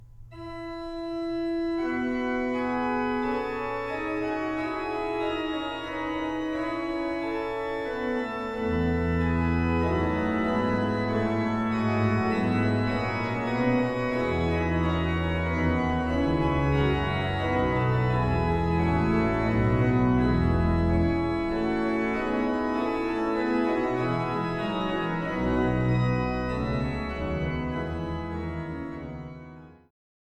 Eule-Orgel der Marienkirche zu Zwickau